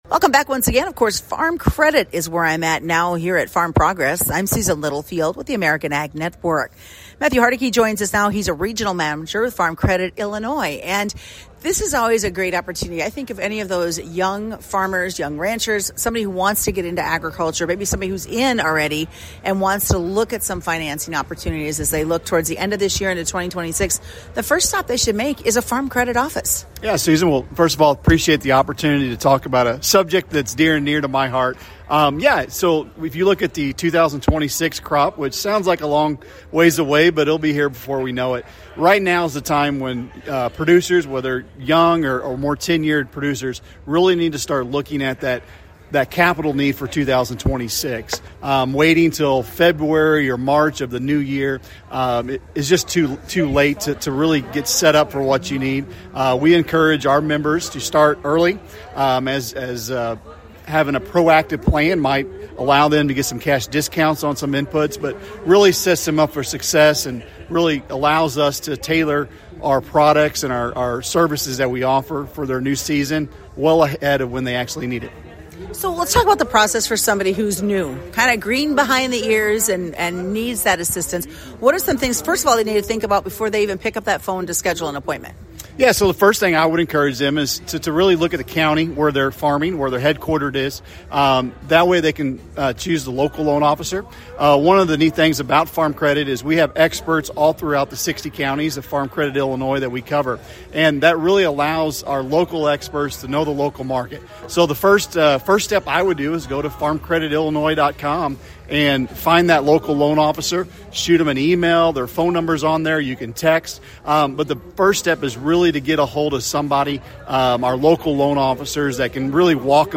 During the 2025 Farm Progress Show, we discussed capital needs for 2026 and more financing opportunities with Farm Credit.